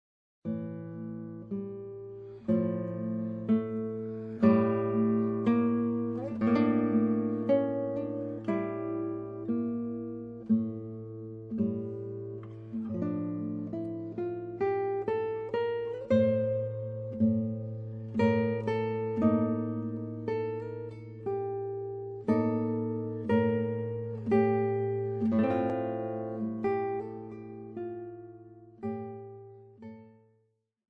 Skladby z 20. století pro klasickou kytaru